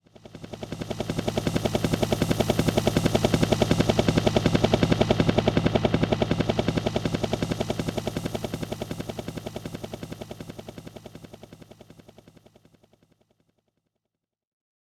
Roland.Juno.D _ Limited Edition _ GM2 SFX Kit _ 09.wav